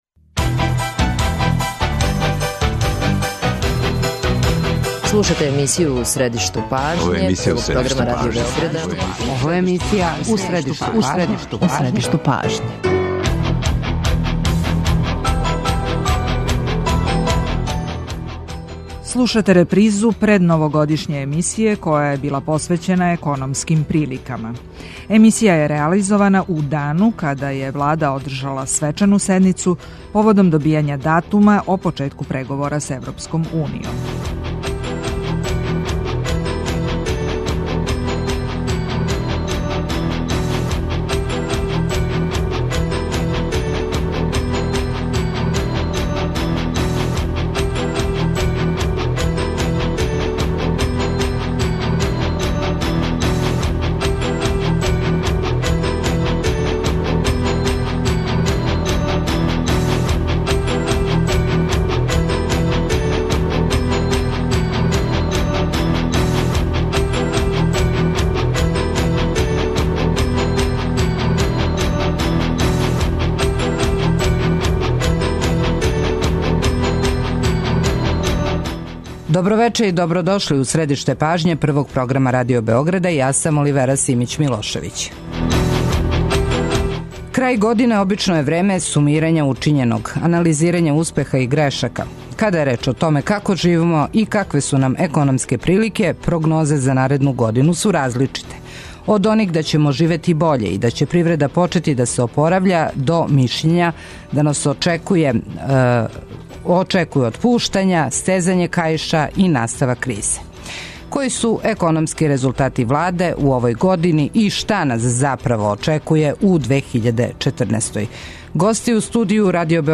Гости емисије, која је реализована крајем 2013. године, били су економисти: Дејан Шошкић, професор Економског факултета и бивши гувернер Народне банке Србије и Душан Вујовић, бивши експерт Светске банке, професор на Факлутету за економију, финансије и администрацију.